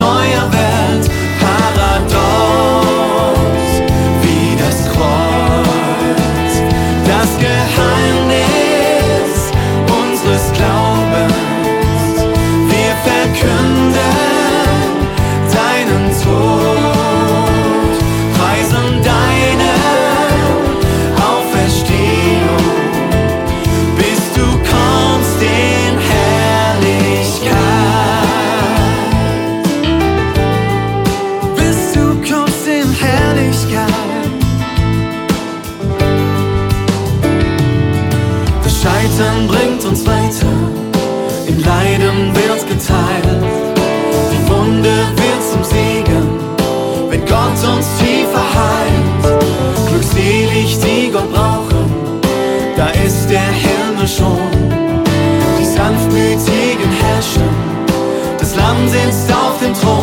Lobpreis